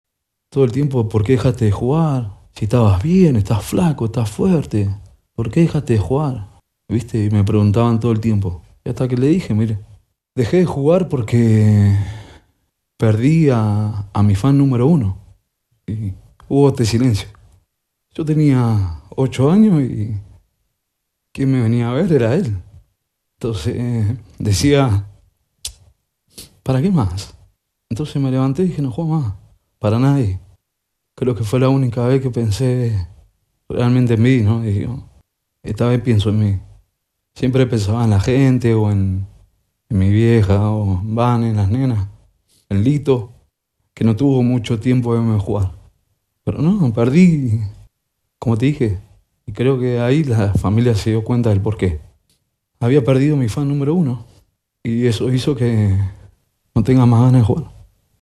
Carlos Tévez - Ex jugador de fútbol, Cortesía America TV